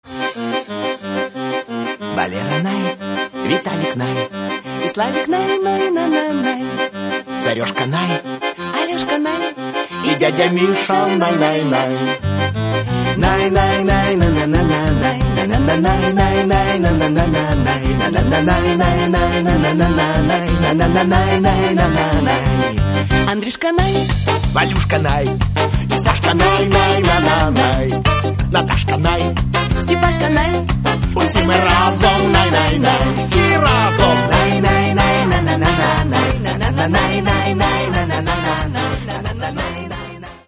Каталог -> Рок та альтернатива -> Поп рок